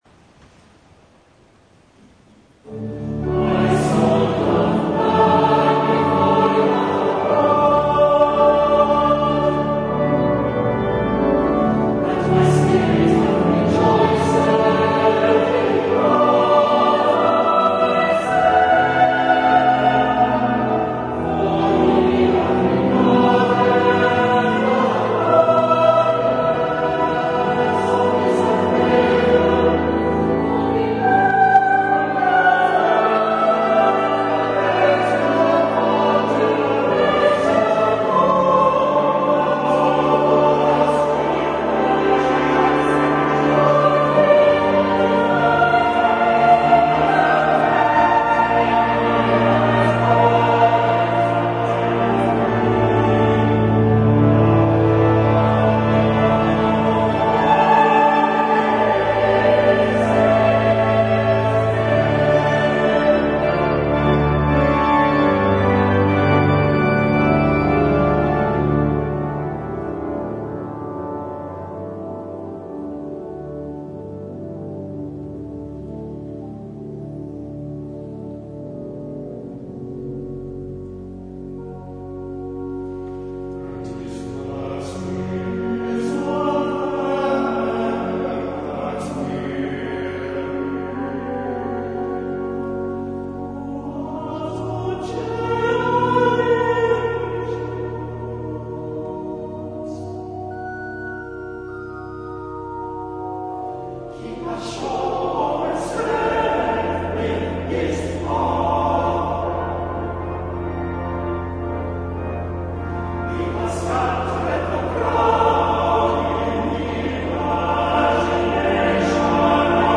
For those recordings made during live services, the permission of the appropriate authority was sought and obtained.